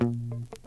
808s
Bass (live).wav